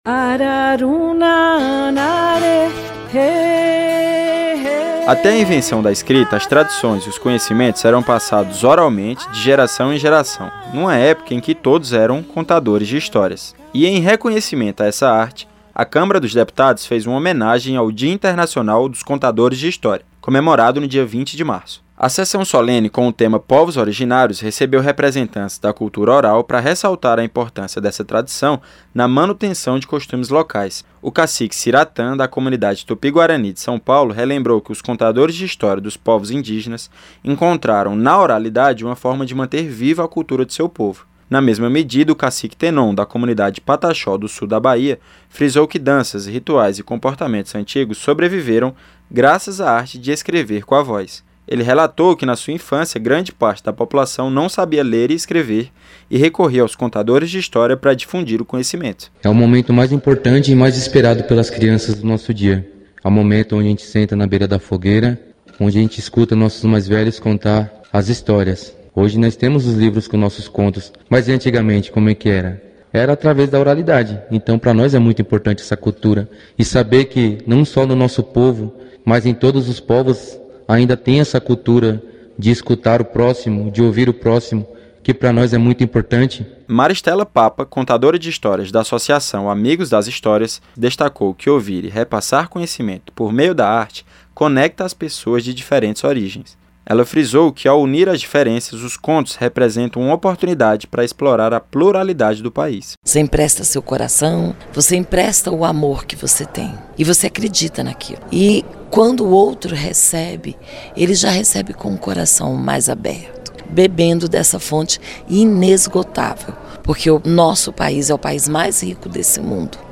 CÂMARA HOMENAGEIA DIA INTERNACIONAL DOS CONTADORES DE HISTÓRIAS. O REPÓRTER